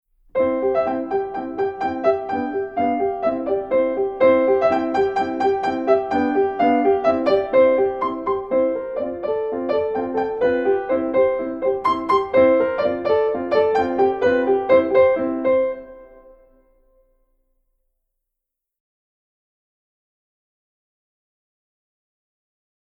Voicing: Piano with Audio Access